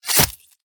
combat / weapons / sb1 / flesh3.ogg
flesh3.ogg